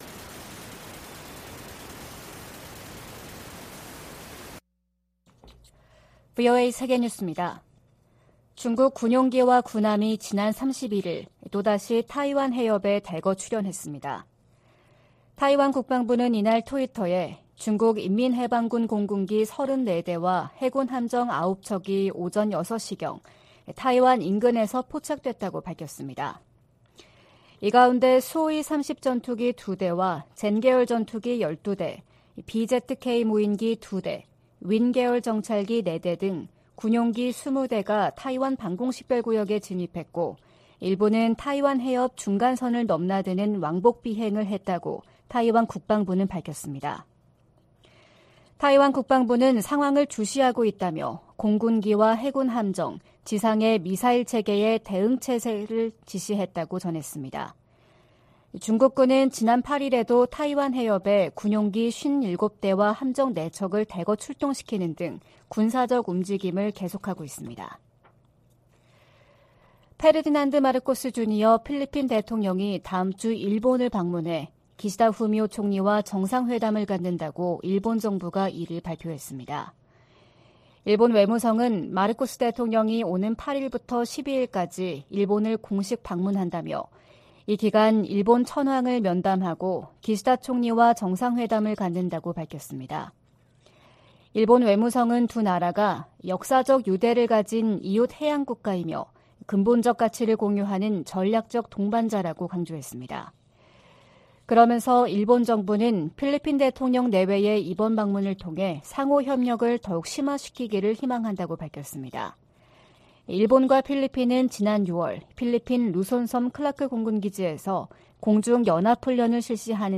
VOA 한국어 '출발 뉴스 쇼', 2023년 2월 2일 방송입니다. 오는 3일 워싱턴에서 열릴 미-한 외교장관 회담에서는 북한의 도발 행위를 억제하는 중국의 역할을 끌어내기 위한 공조 외교를 펼 것이라는 관측이 나오고 있습니다. 미국 국무부가 한국에서 독자 핵 개발 지지 여론이 확대되는 것과 관련해, 핵무장 의지가 없다는 윤석열 정부의 약속을 상기시켰습니다.